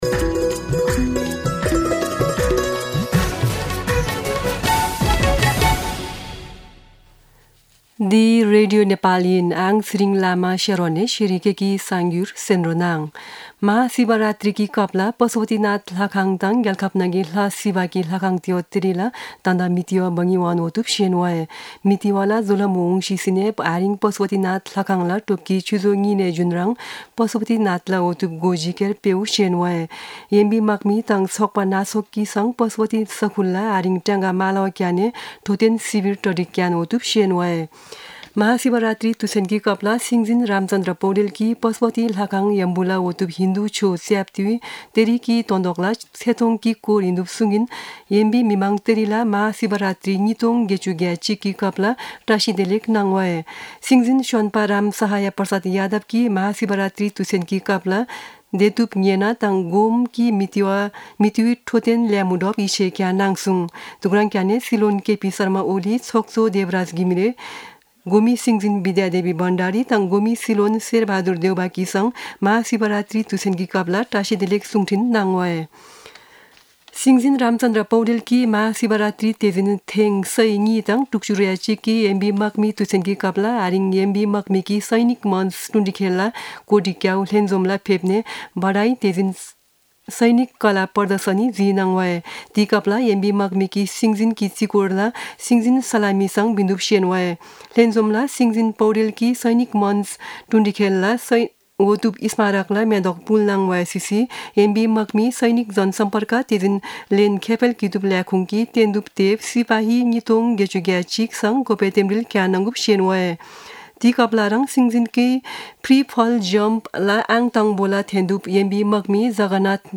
शेर्पा भाषाको समाचार : १५ फागुन , २०८१
sherpa-News-14.mp3